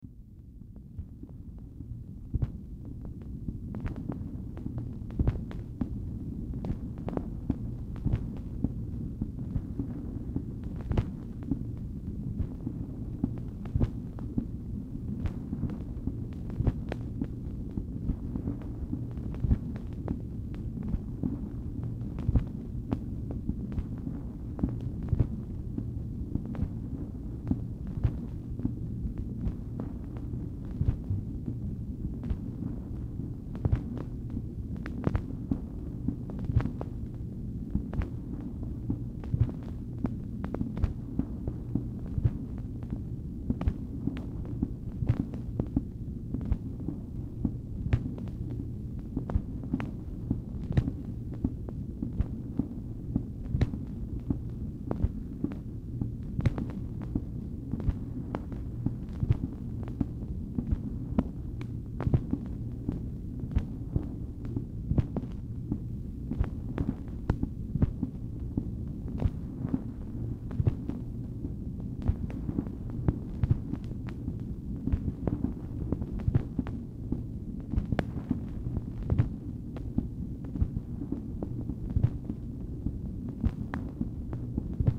Telephone conversation # 1942, sound recording, MACHINE NOISE, 2/7/1964, time unknown | Discover LBJ
Format Dictation belt
Specific Item Type Telephone conversation